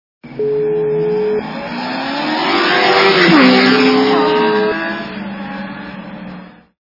» Звуки » Авто, мото » Звук - Проносящегося мимо Гоночного Мотоцикла
При прослушивании Звук - Проносящегося мимо Гоночного Мотоцикла качество понижено и присутствуют гудки.